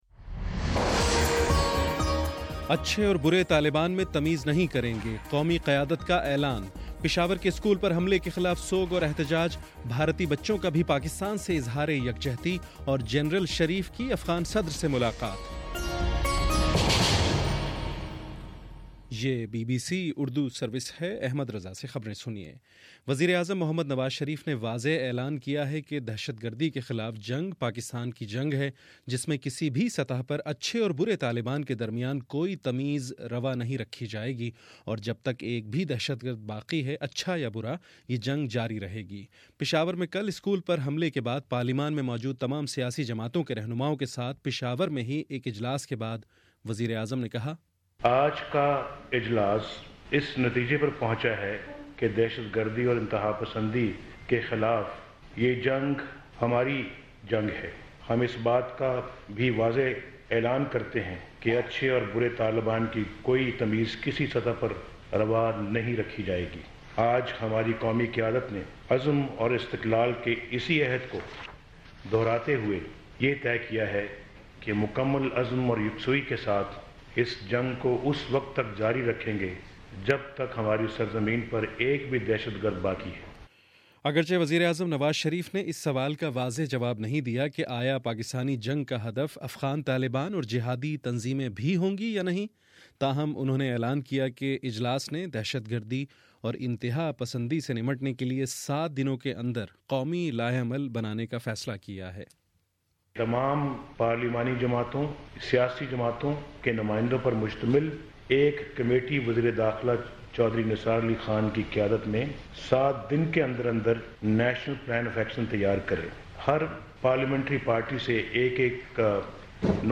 دسمبر17: شام سات بجے کا نیوز بُلیٹن